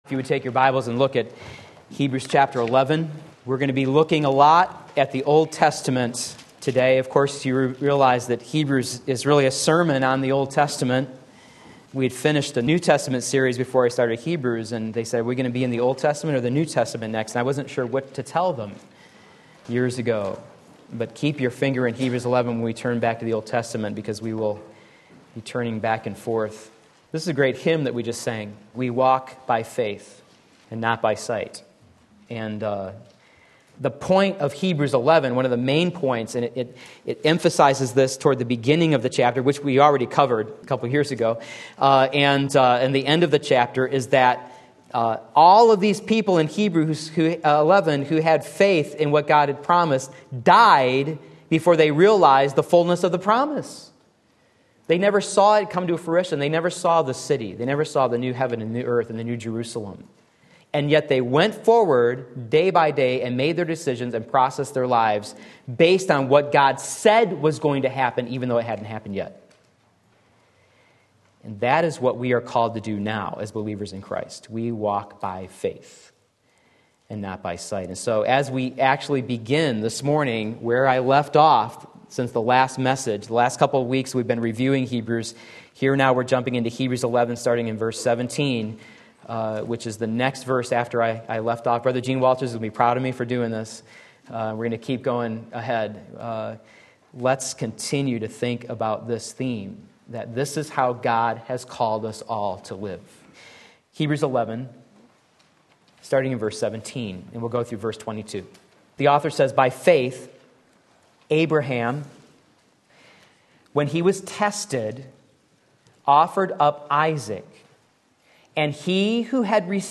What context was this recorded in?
Sunday Morning Service